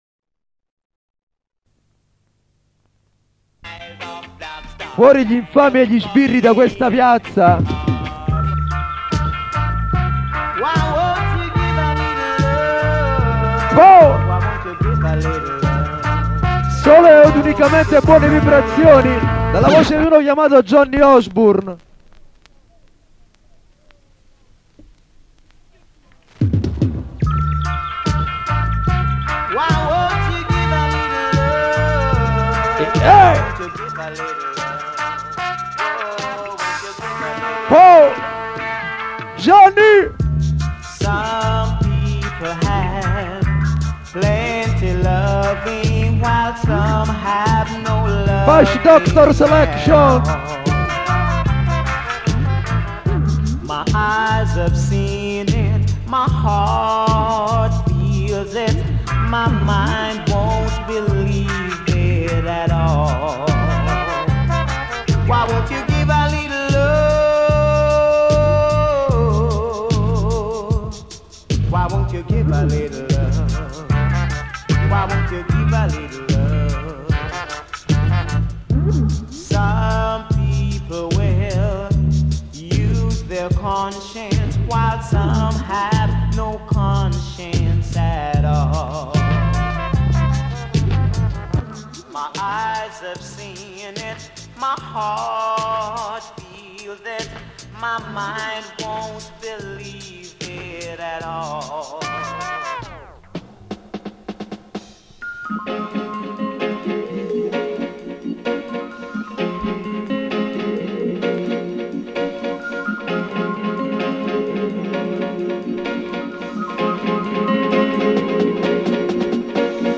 Dancehall in the street - 2006 Napoli
Dancehall
original SOUND SYSTEM in the street.